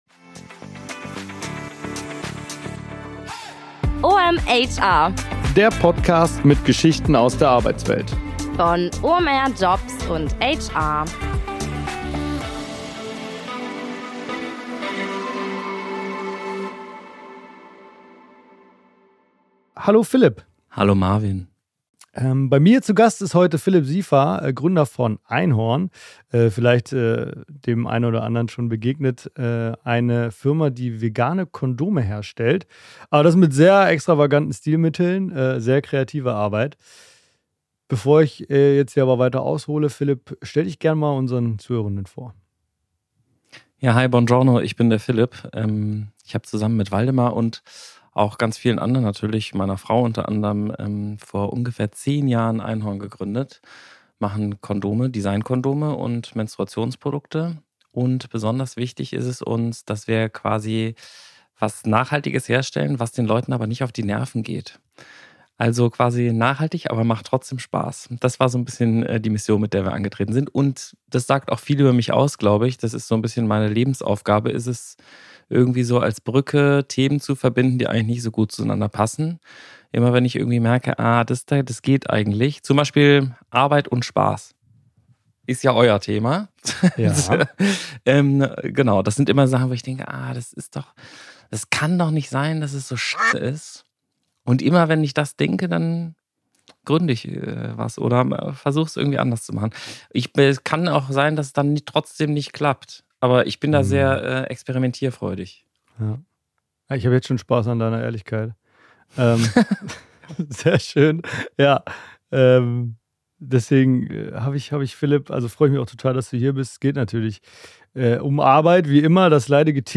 Interview
Ein launiges Gespräch über Spielplätze, Gummizellen, sterbende Automarken und vor allem eines: Die Tücken von New Work.